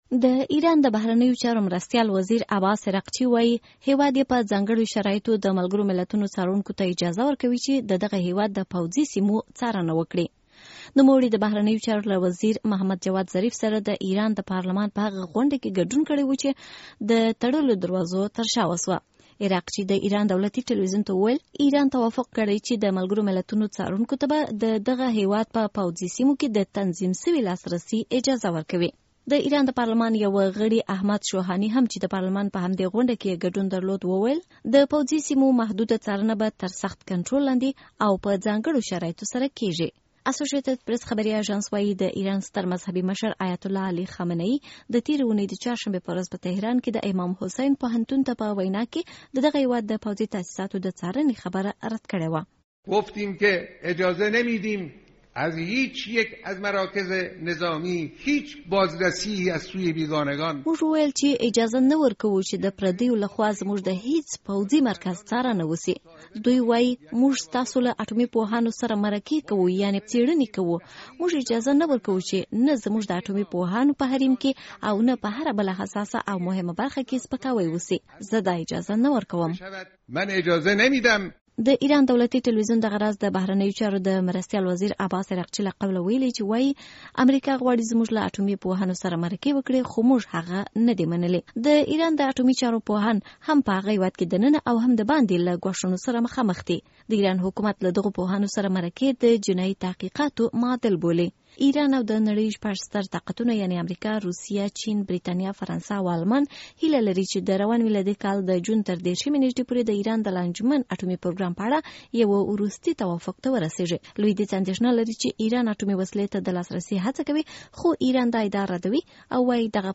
د راپور جزییات